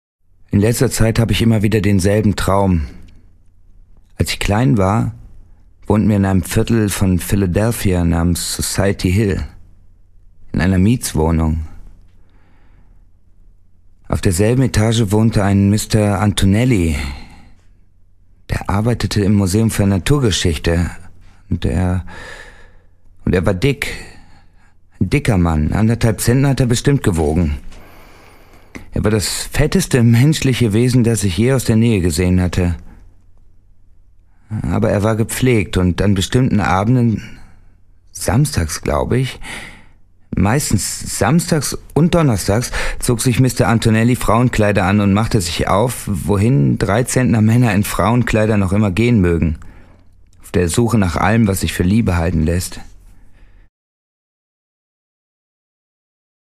Stimme mit Wiedererkennungswert, großer Flexibilität und Zuverlässigkeit.
Sprechprobe: eLearning (Muttersprache):